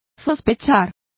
Complete with pronunciation of the translation of surmising.